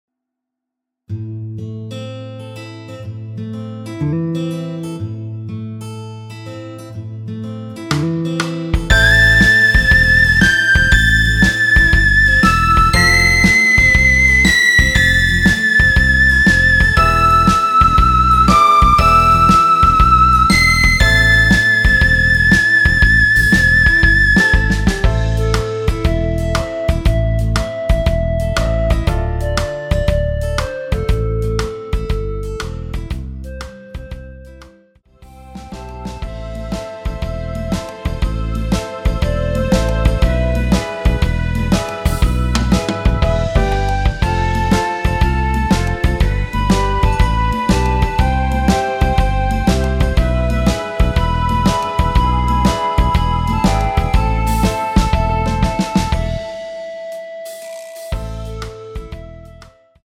엔딩이 페이드 아웃으로 끝나서 라이브에 사용하실수 있게 엔딩을 만들어 놓았습니다.
원키에서(+5)올린 멜로디 포함된 MR입니다.
Am
앞부분30초, 뒷부분30초씩 편집해서 올려 드리고 있습니다.
중간에 음이 끈어지고 다시 나오는 이유는